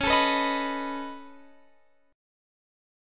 C#7sus2#5 chord